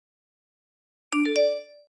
phone_msg_notification.mp3